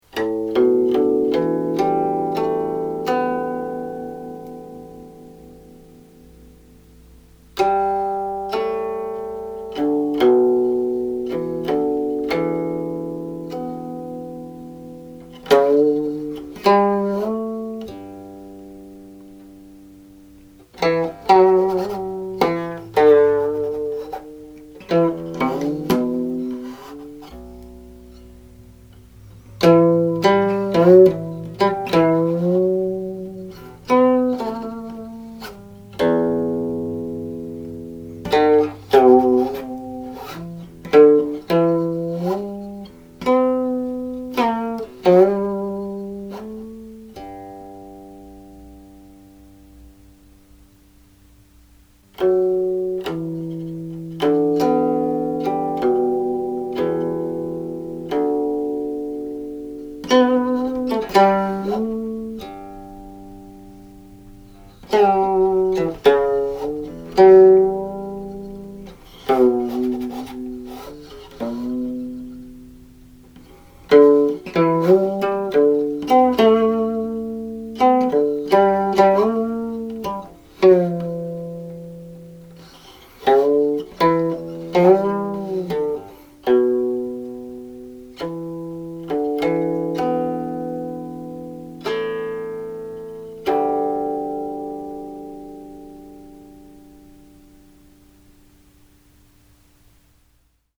(timings follow the recording of my adaptation for qin